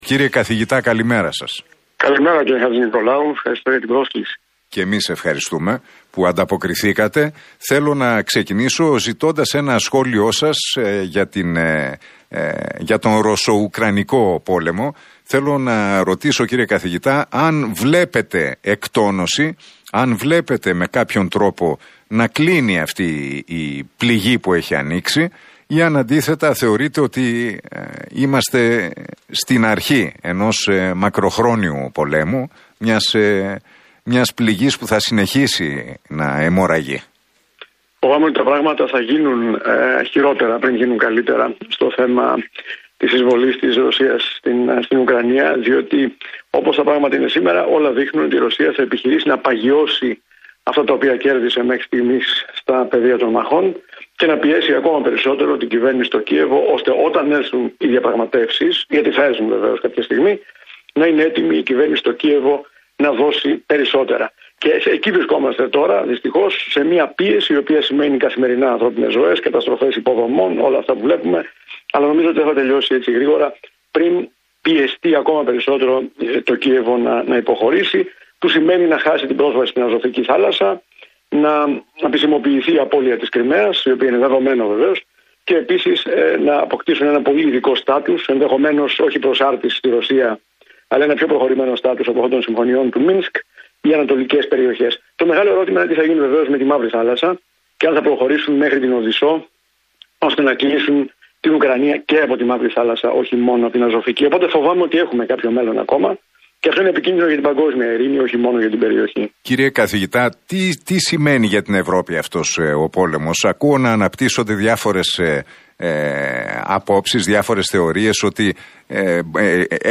μιλώντας στον Realfm 97,8 και την εκπομπή του Νίκου Χατζηνικολάου αναφέρθηκε στις επιπτώσεις του πολέμου στην Ουκρανία.